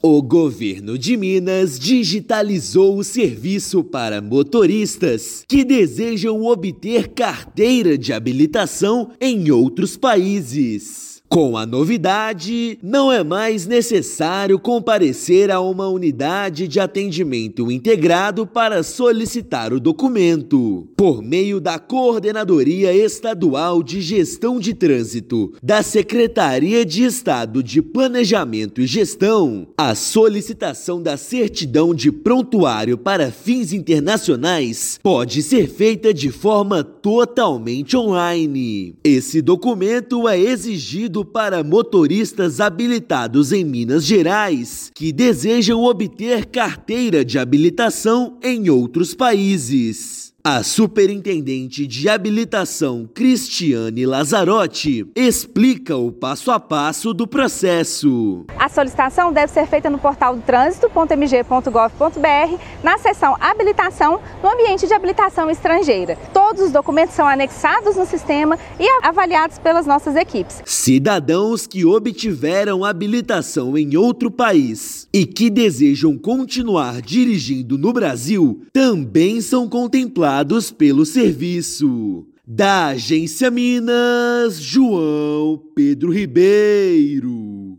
Certidão de prontuário para fins internacionais passa a ser emitida de forma digital, com mais rapidez e segurança. Ouça matéria de rádio.